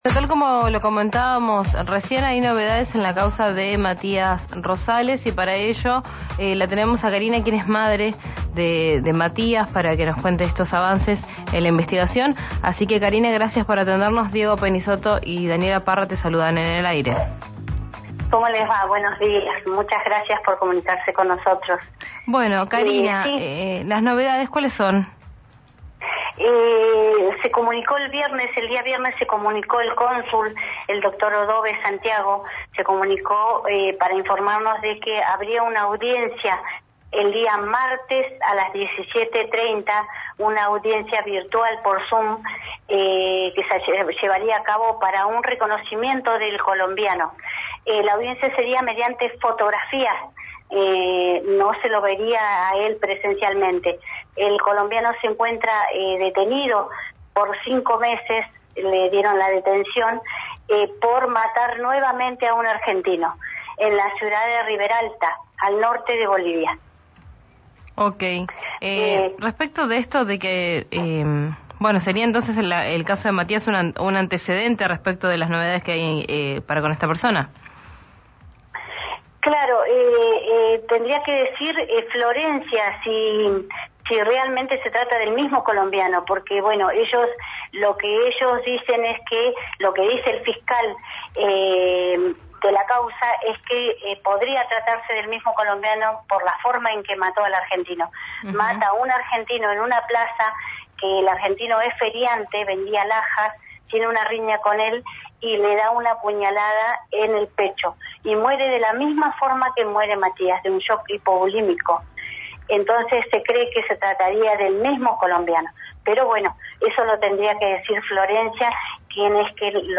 María Emilia Soria, intendenta de Roca, hizo un análisis de la situación política de la región, en una entrevista exclusiva con RÍO NEGRO RADIO.
En una entrevista exclusiva con la intendenta de Roca, María Emilia Soria, en el programa «Quién dijo verano» de RÍO NEGRO RADIO, la mandataria detalló los desafíos y la crisis que enfrenta el municipio en un contexto de incertidumbre económica. La funcionaria abordó temas cruciales que van desde la quita de subsidios en el transporte, la emergencia alimentaria, hasta las complejidades salariales del personal municipal.